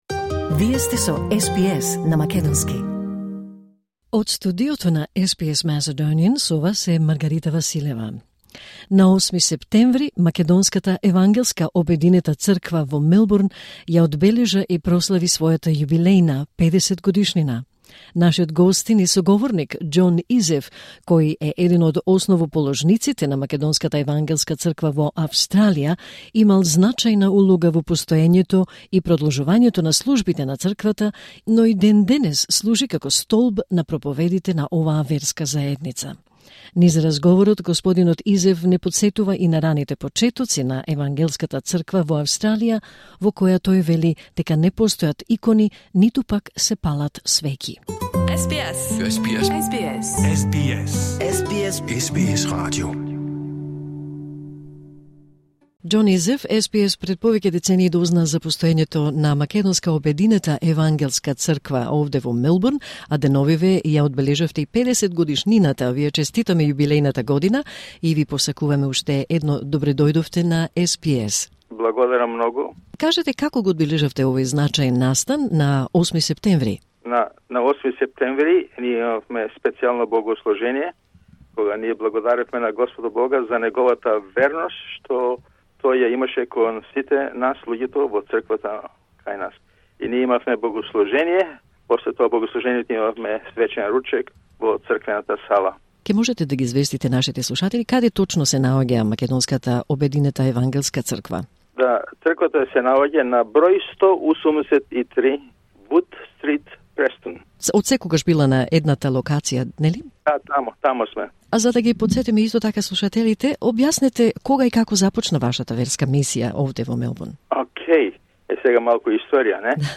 Низ разговорот